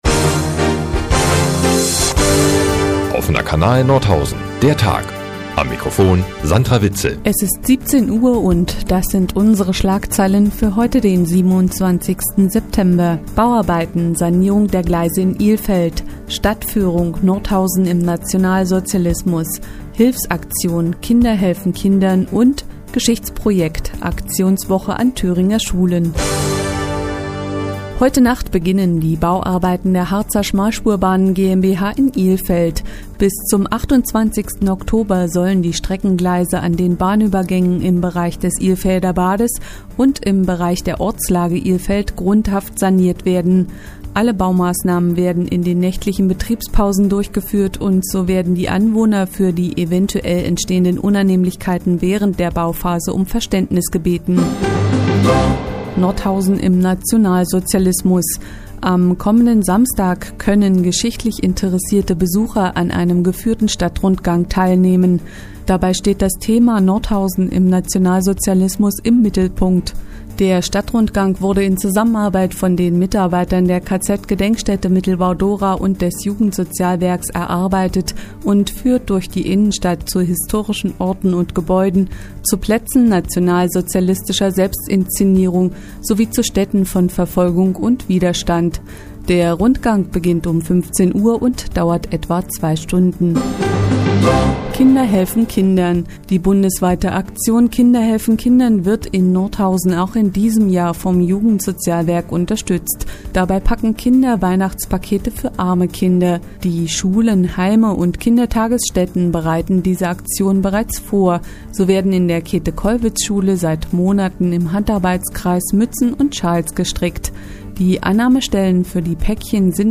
Die tägliche Nachrichtensendung des OKN ist auch in der nnz zu hören.